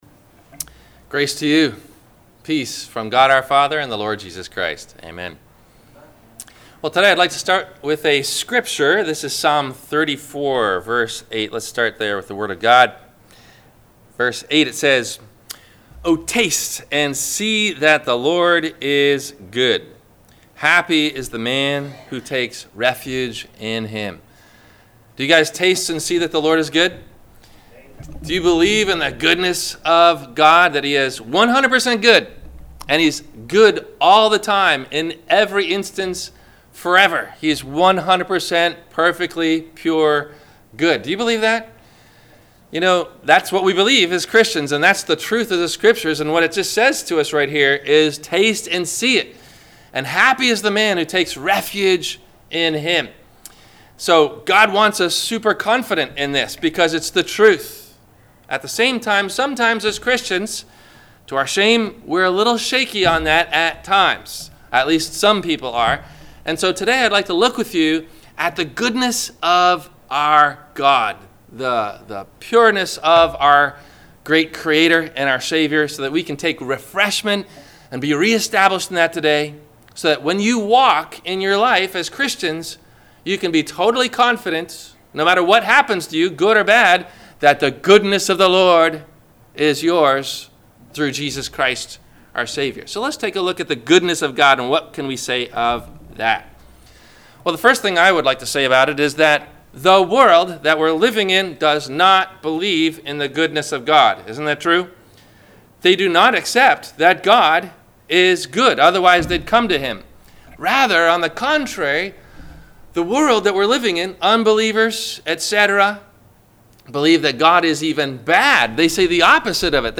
Listen for these questions and answers for “Is God Truly Good?” , below in the 1-part Video , or the one-part MP3 Audio Sermon.